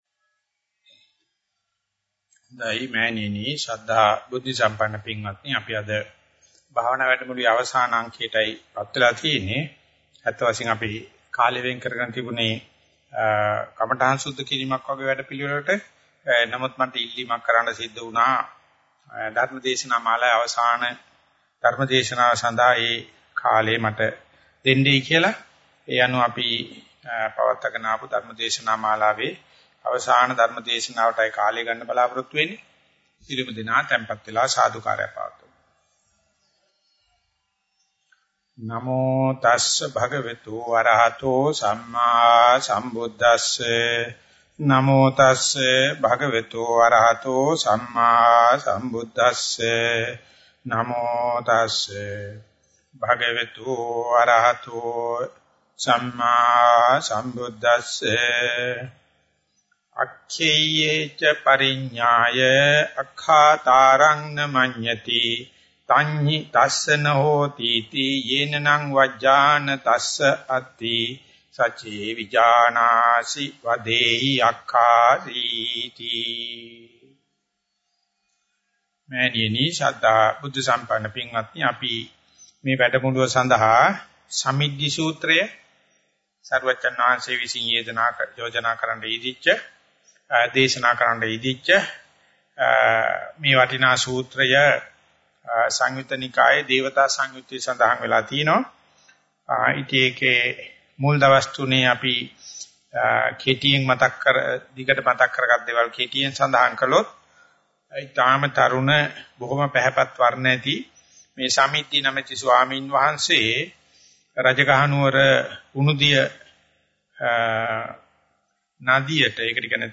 These short meditation retreats are organized for the benefit of those who are relatively new to meditation practice.
06.DhammaTalk_04_SamiddhiSutta_Part 1.mp3